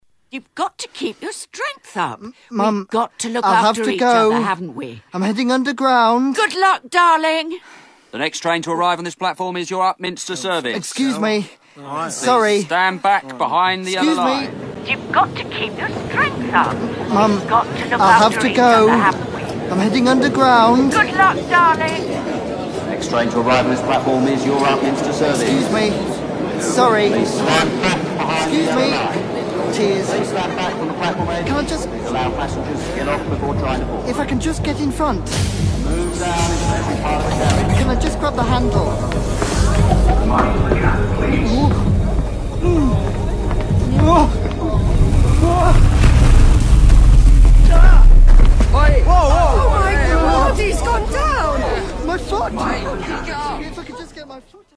Sound Design in a radio play
"Sometimes a drama goes the extra distance when it comes to sound design, enabling the listener to picture each scene and each location so perfectly.